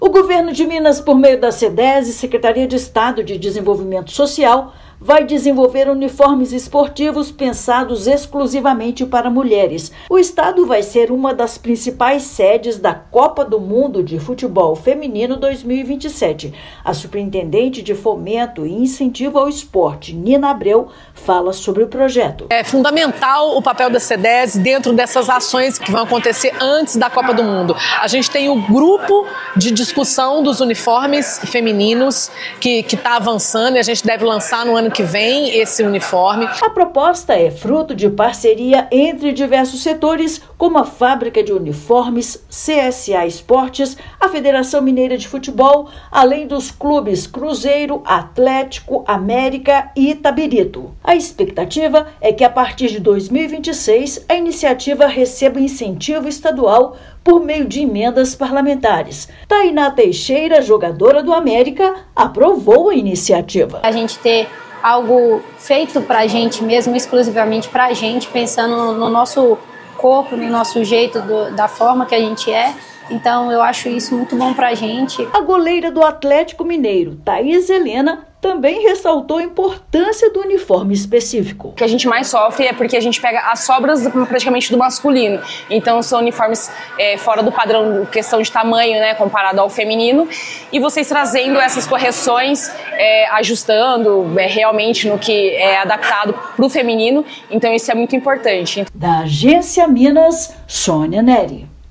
Iniciativa pioneira liderada pela Sedese valoriza o protagonismo das mulheres dentro e fora do esporte. Ouça matéria de rádio.